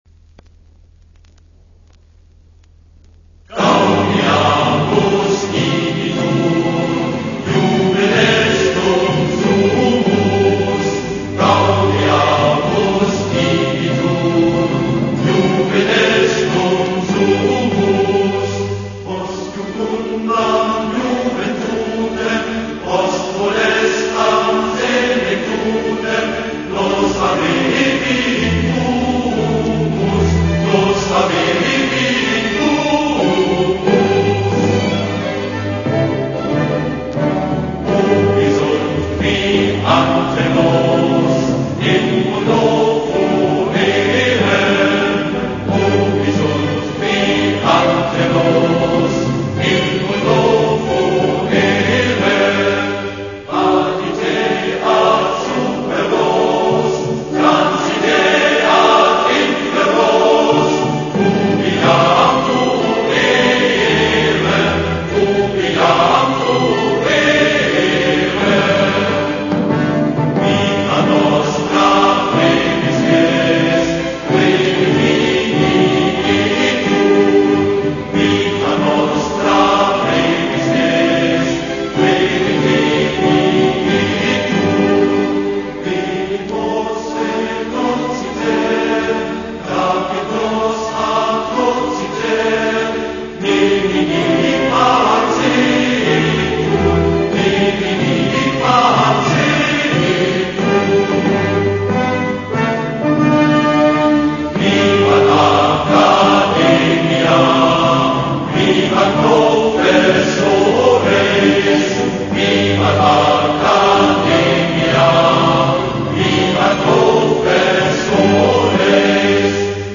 Stredoveká študentská pieseň.